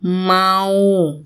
– mau